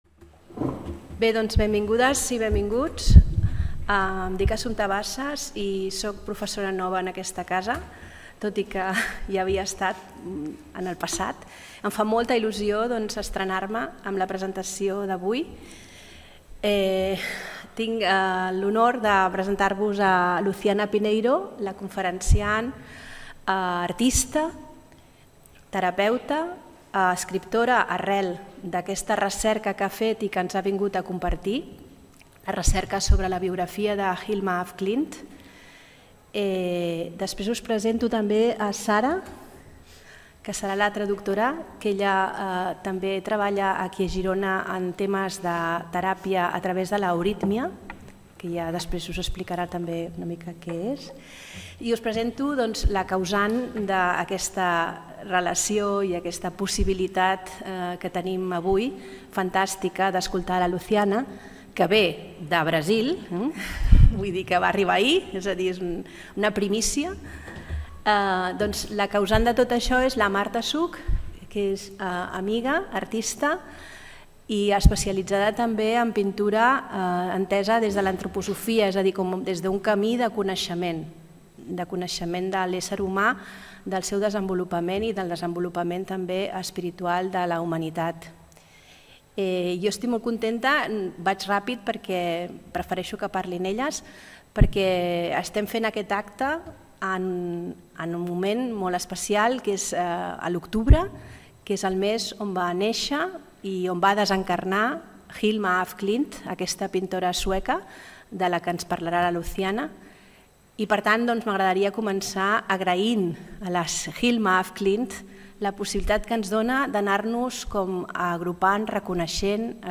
La vida de Hilma af Klint. Colores del Alma (conferència i conversa)